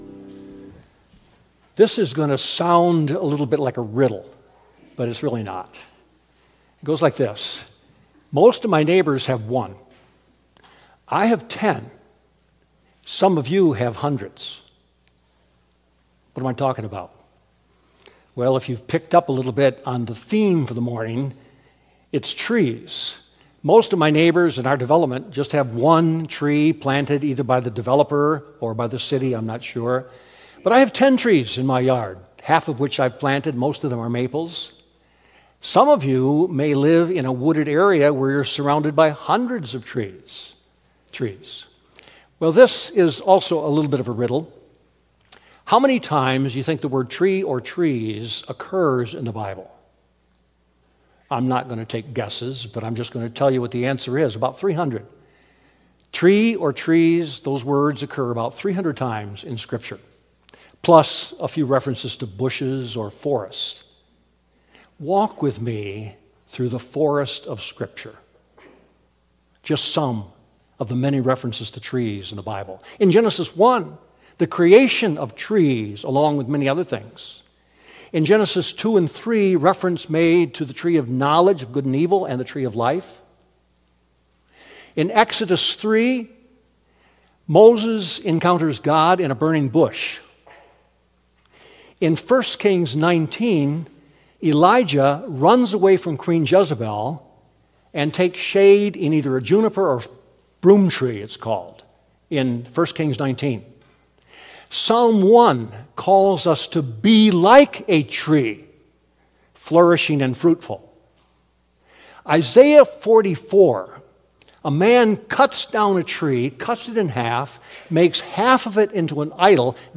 Sermon Recordings | Faith Community Christian Reformed Church
“The TREE of All Trees!” April 12 2025 A.M. Service